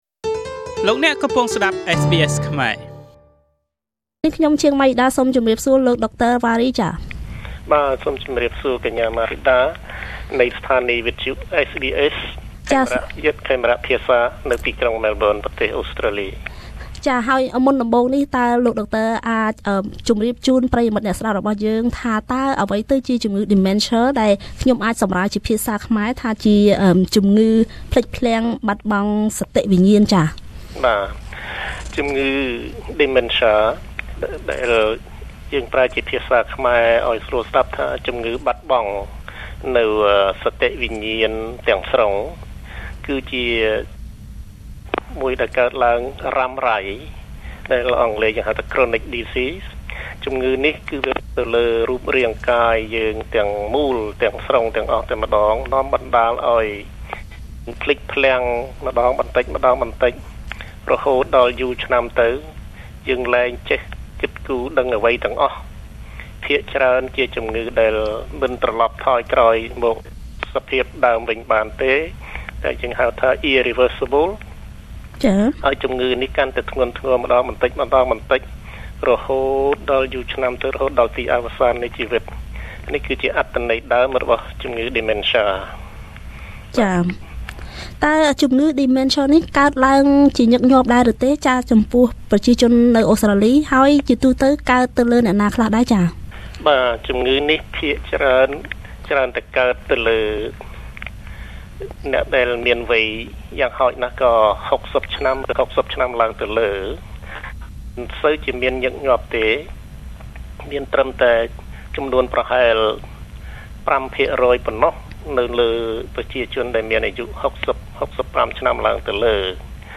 តើជំងឺនេះមានប៉ុន្មានប្រភេទ ហើយវាកើតឡើងលើមនុស្សវ័យណាខ្លះ? សូមស្តាប់បទសម្ភាសន៍